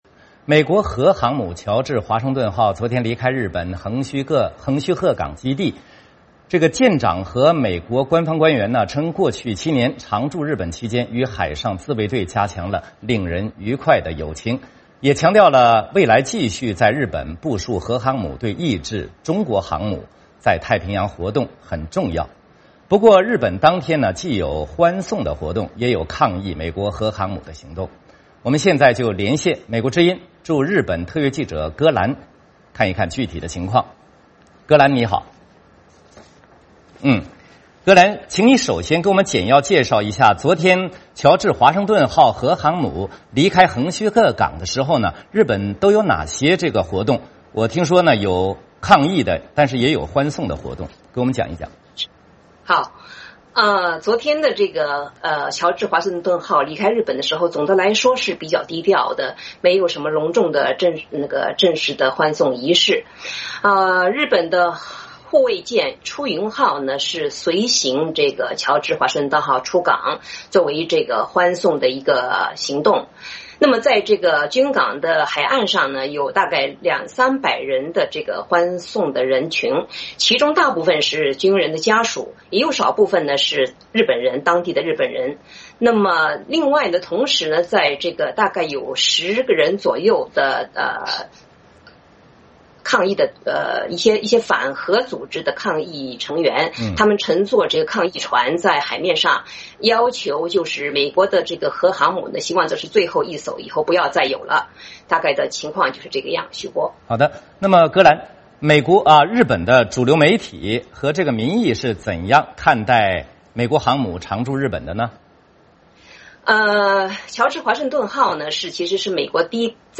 VOA连线：日本民众抗议横须贺美军基地